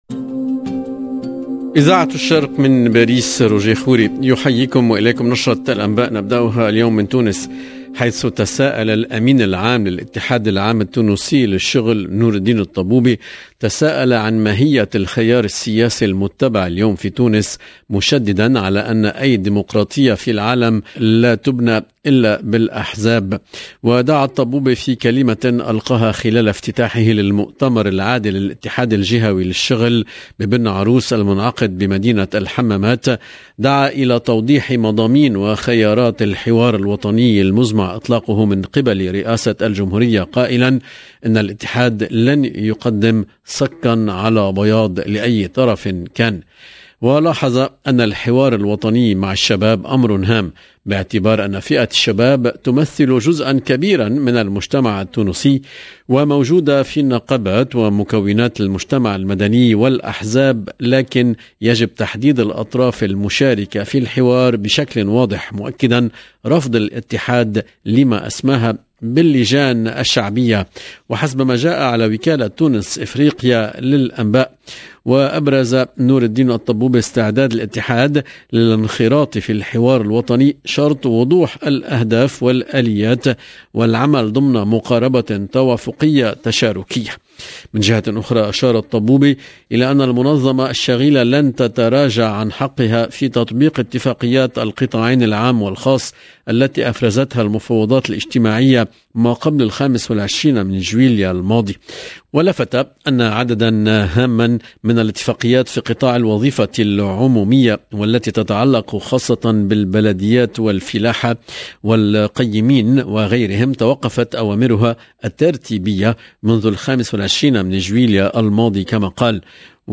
LE JOURNAL DU SOIR EN LANGUE ARABE DU 24/10/2021
JOURNAL SOIR LANGUE ARABE 24/10/2021